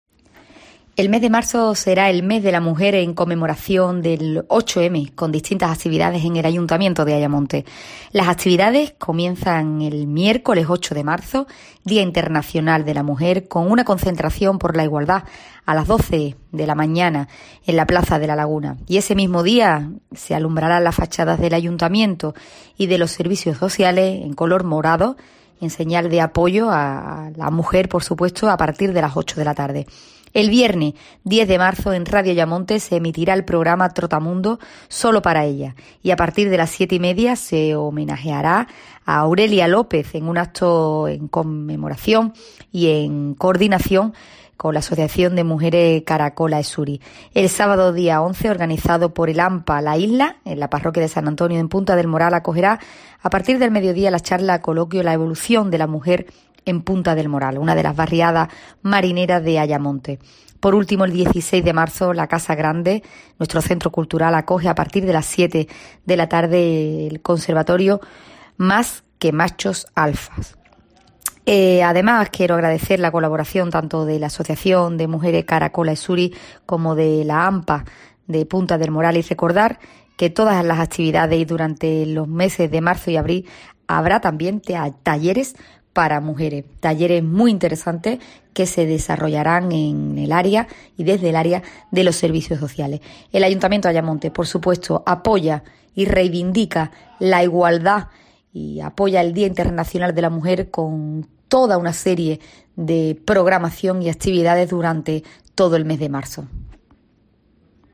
Natalia Santos, alcaldesa de Ayamonte